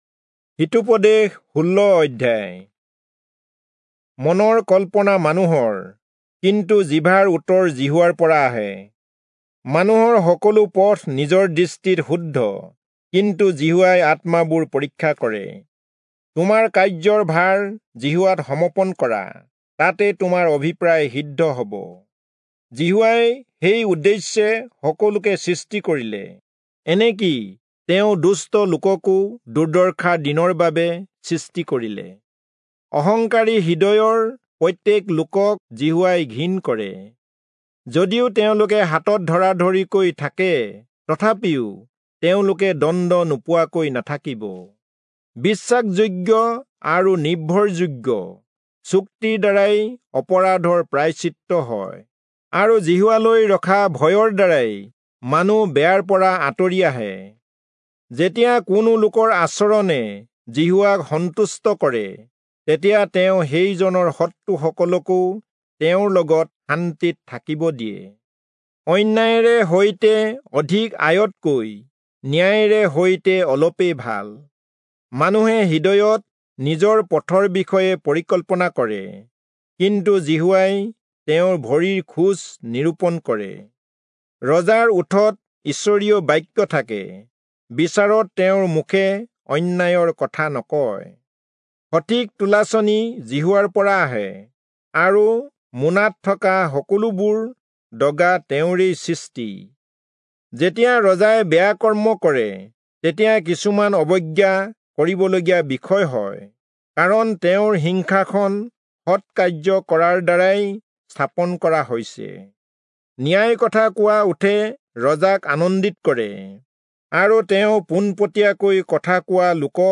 Assamese Audio Bible - Proverbs 5 in Tov bible version